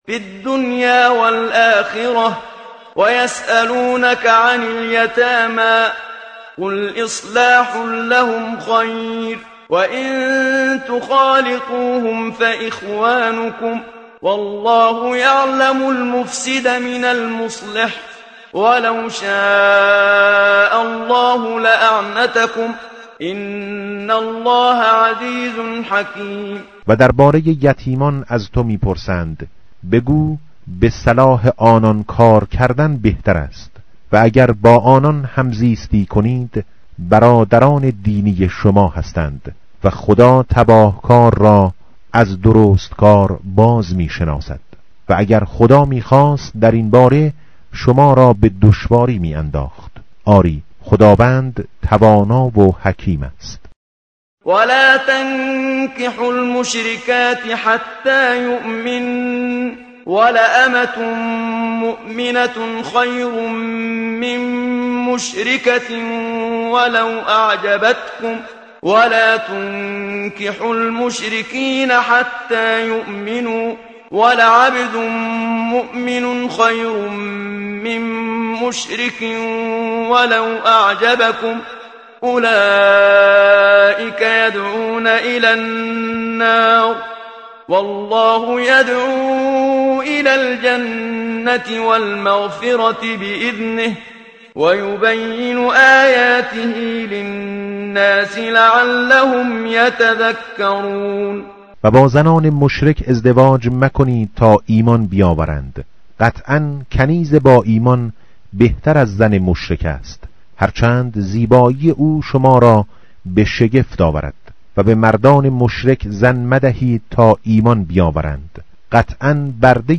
tartil_menshavi va tarjome_Page_035.mp3